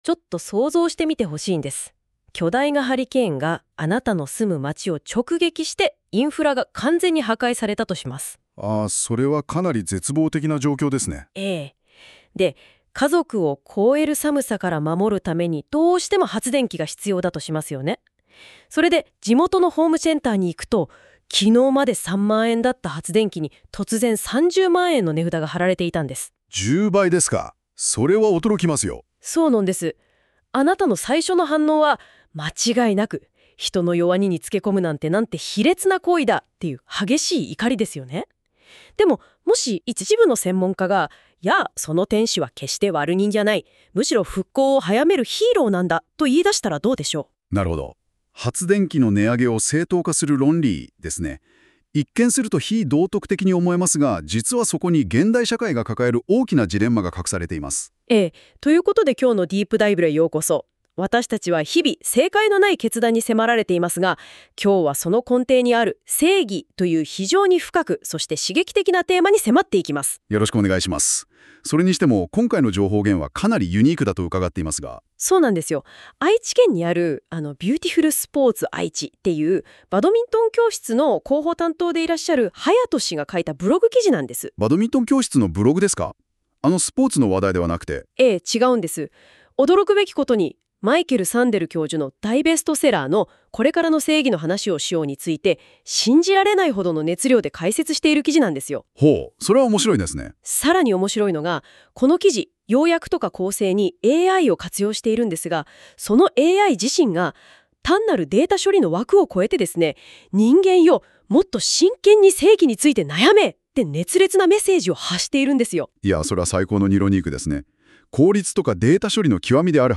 AIが熱弁する正解のない正義論.m4a